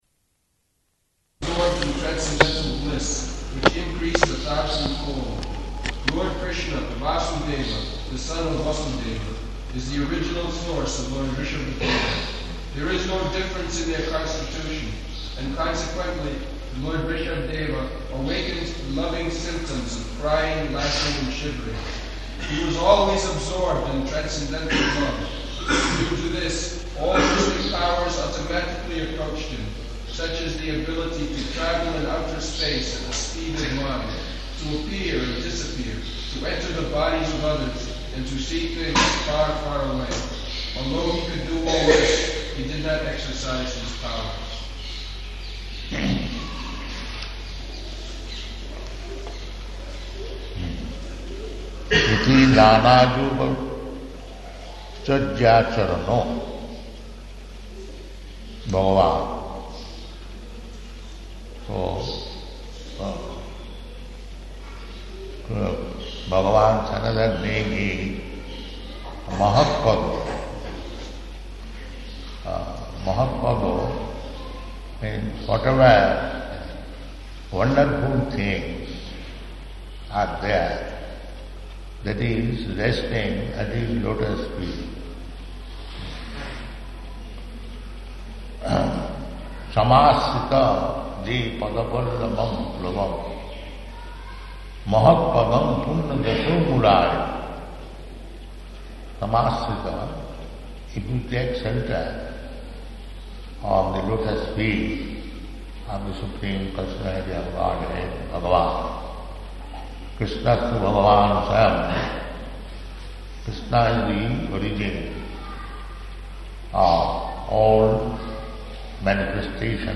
November 22nd 1976 Location: Vṛndāvana Audio file